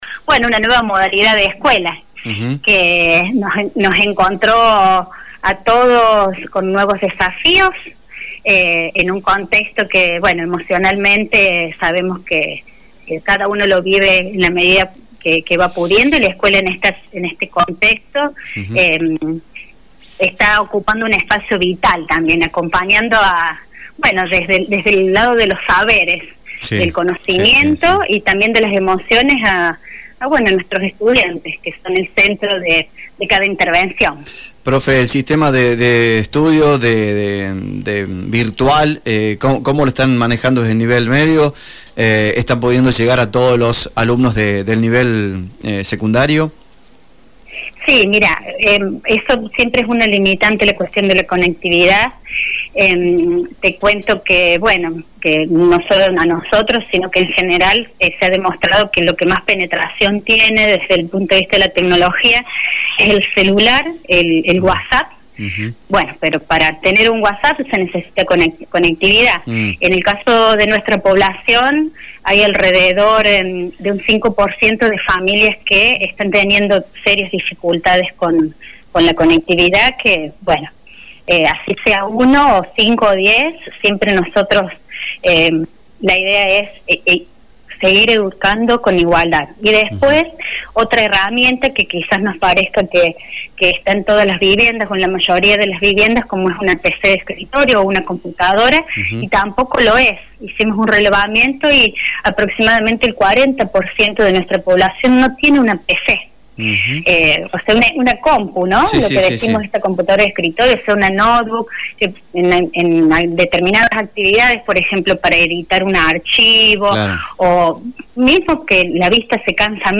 dialogó con FM Samba y brindó detalles del presente de la institución y como están llevando adelante las clases virtuales.